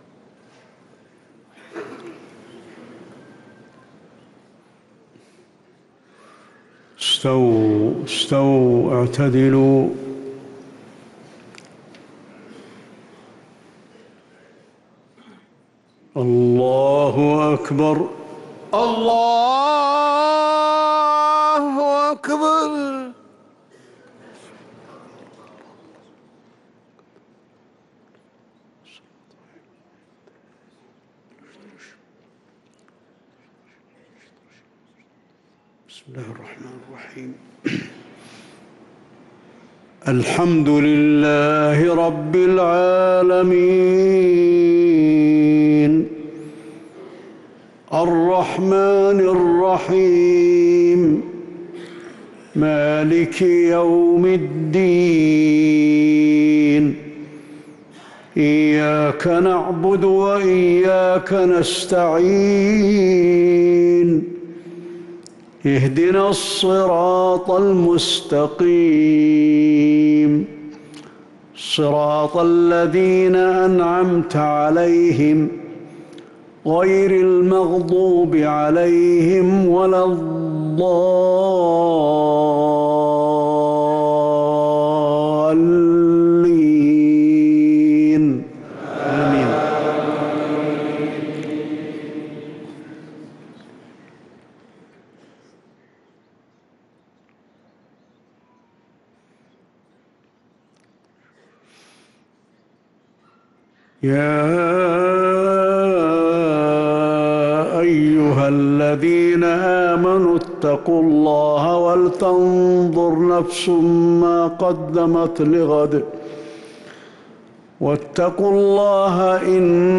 صلاة العشاء للقارئ علي الحذيفي 11 شعبان 1444 هـ